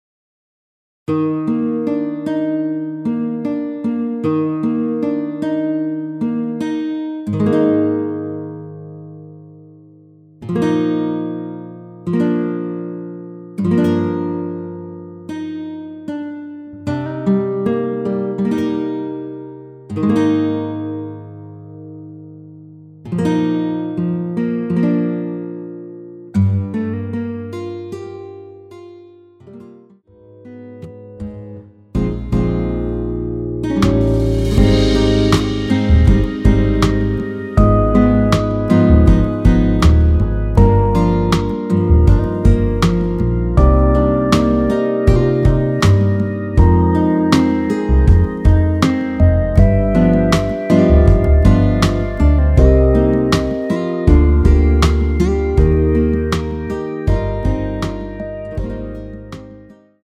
전주 없이 시작하는 곡이라 라이브 하기 좋게 전주 2마디 만들어 놓았습니다.(미리듣기 확인)
앞부분30초, 뒷부분30초씩 편집해서 올려 드리고 있습니다.
중간에 음이 끈어지고 다시 나오는 이유는